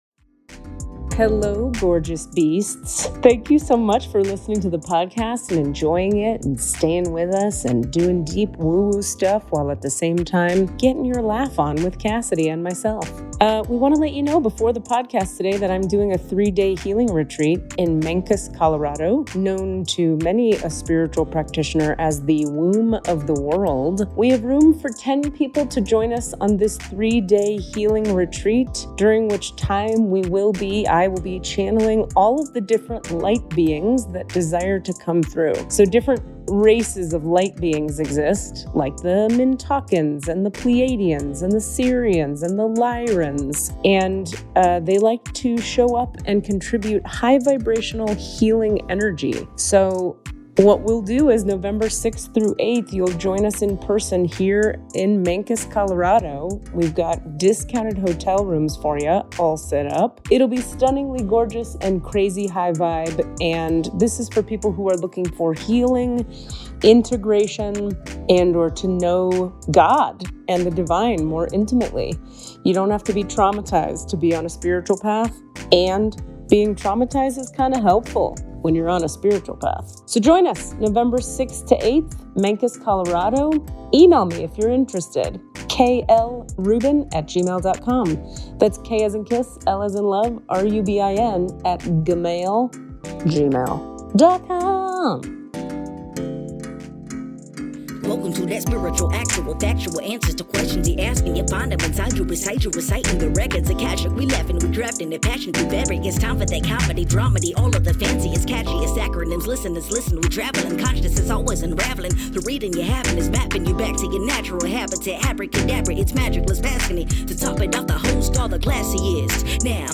so we decided to bring her voice all the way from Australia and do it “live”.